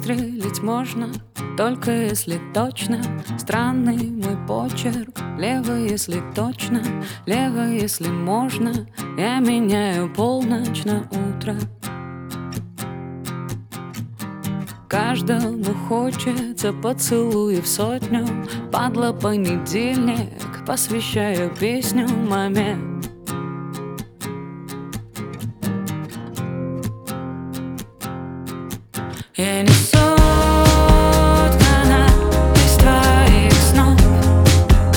Жанр: Поп музыка / Альтернатива / Русский поп / Русские
Indie Pop, Alternative